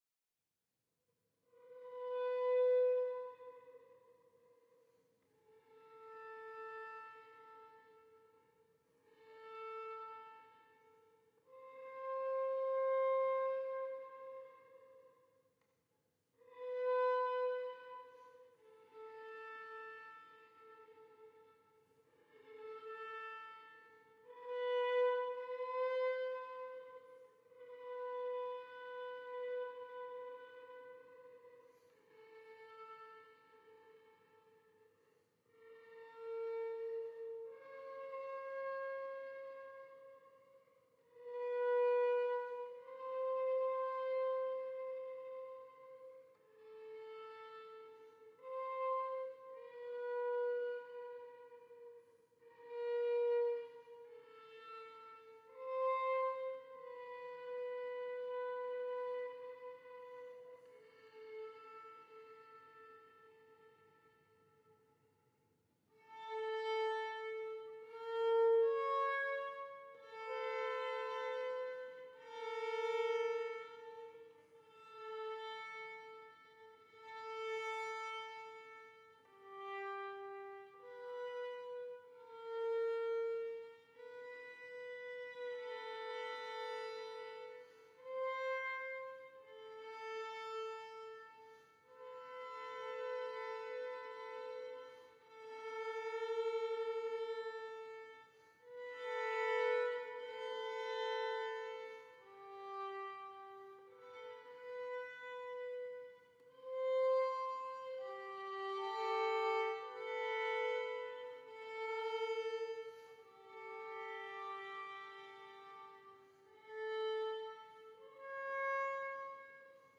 World Premiere- St Vedast Foster Lane
Recording in St Michael’s HIghgate